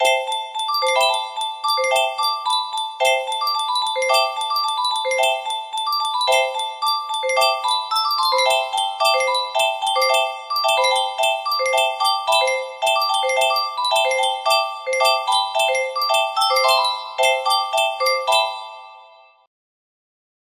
music box melody
Grand Illusions 30 music boxes More